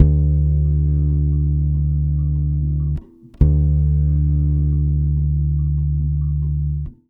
140BASS D7 5.wav